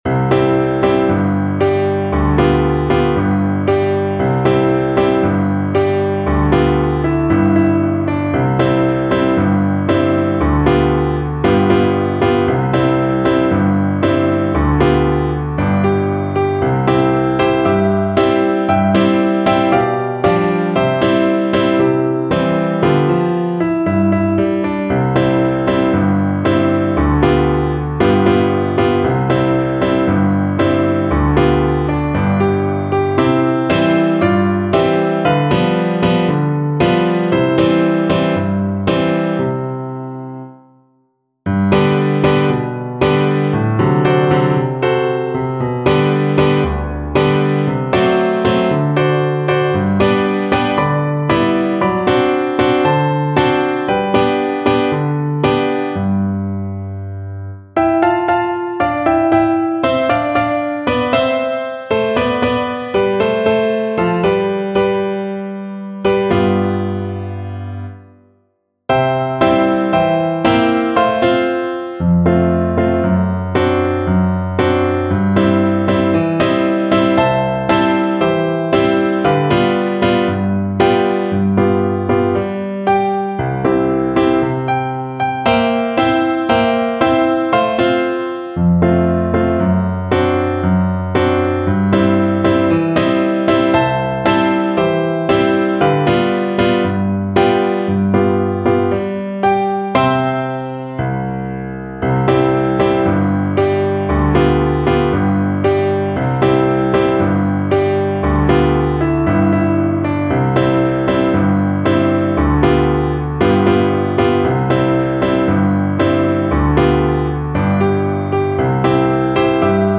Violin
C major (Sounding Pitch) (View more C major Music for Violin )
2/4 (View more 2/4 Music)
World (View more World Violin Music)
Brazilian Choro for Violin
menina_faceira_VLN_kar3.mp3